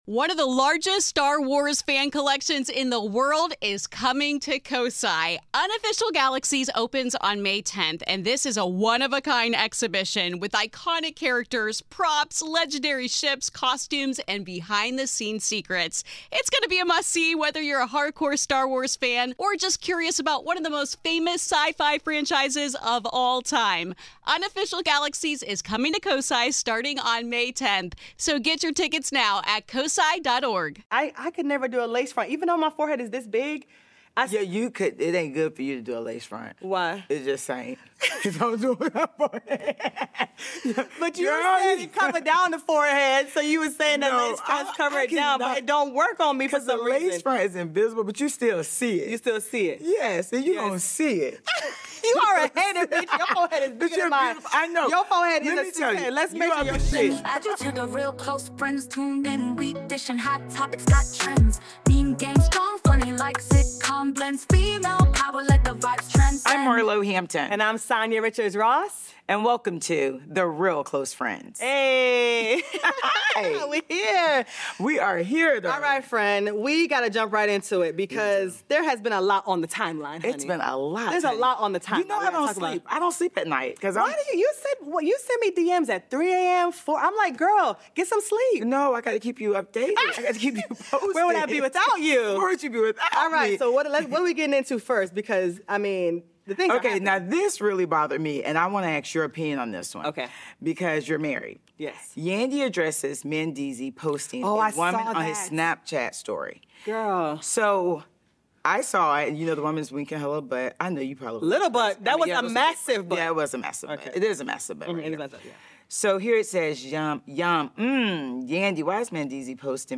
unfiltered conversations with real friends who aren’t afraid to speak their minds.